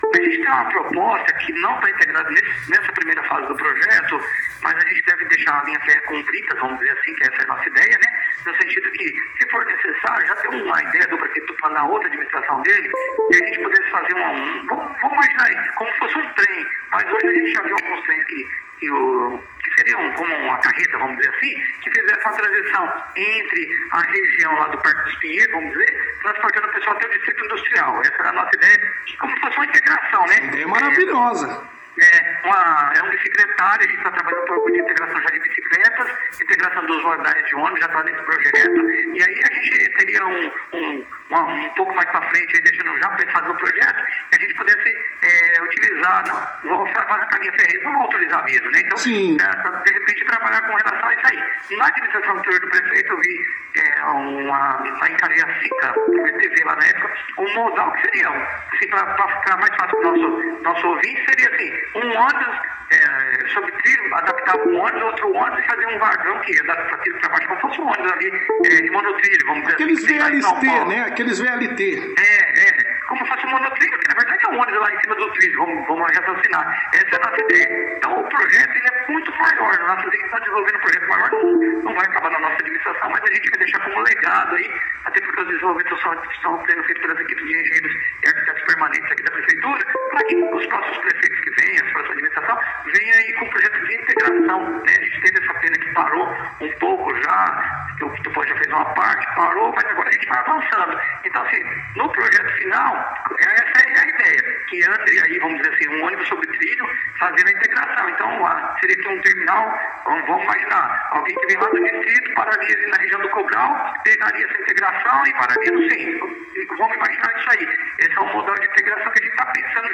O secretário Laércio Alcântara, deu detalhes como seria a idéia do monotrilho: